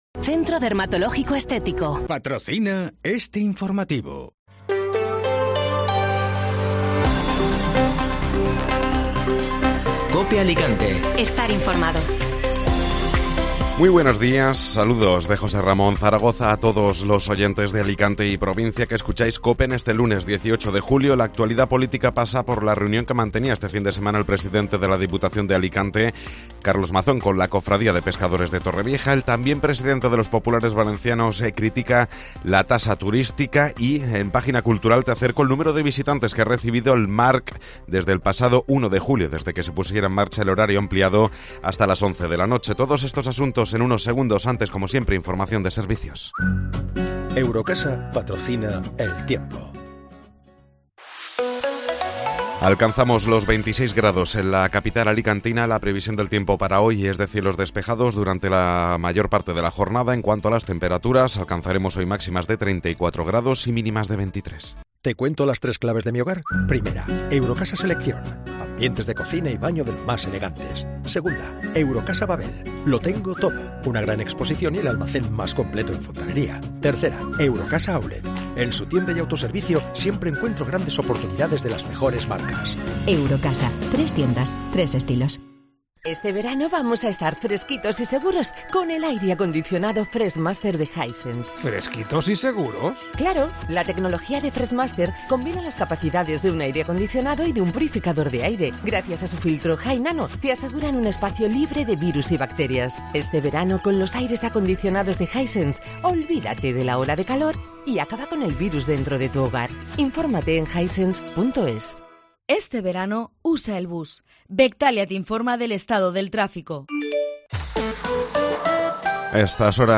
Informativo Matinal (Lunes 18 de Julio)